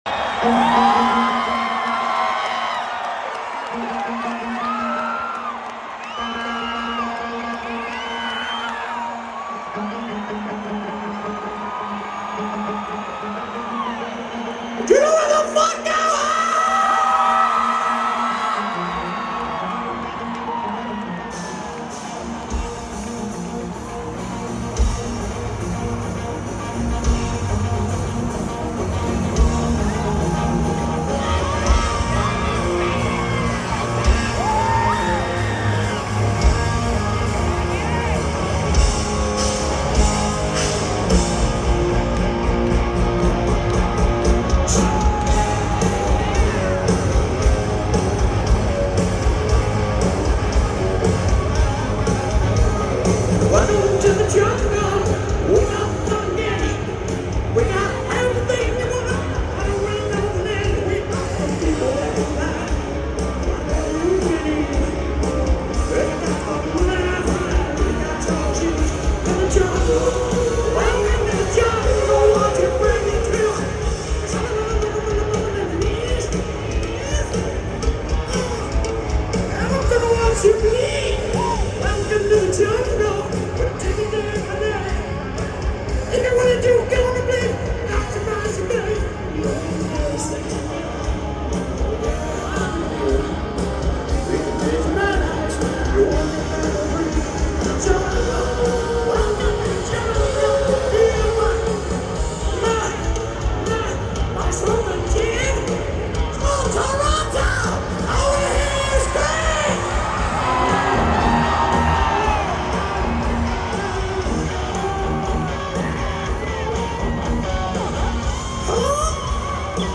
Show did not disappoint!!!